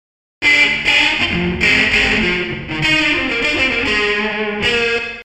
（キー F /テンポ 100 に変更）
1. AmpSim → Comp → Chorus → Reverb
リバーブは、プリセットのままなので非現実的なくらい濃い目ですね。